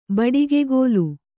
♪ baḍigegōlu